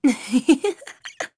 Aselica-Vox_Happy1.wav